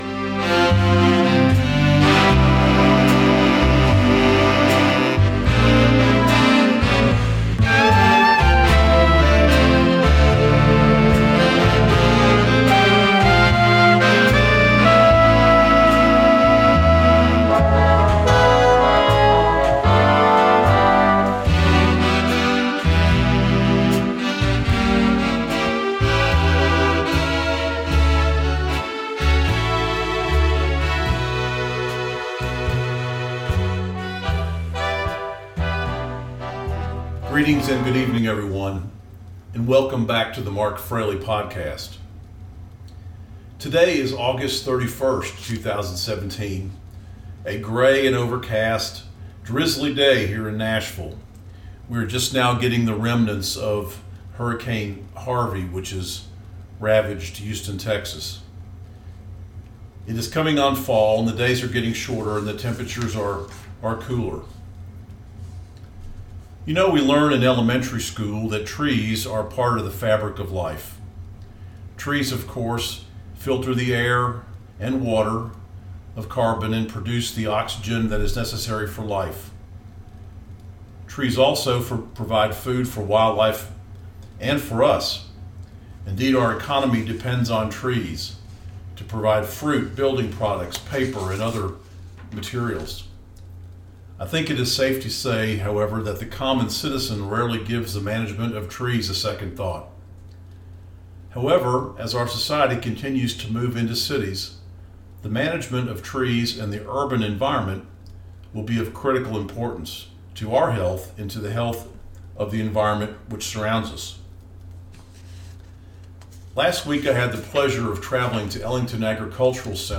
Tennessee Urban Forestry, Interview